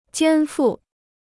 肩负 (jiān fù): porter; supporter.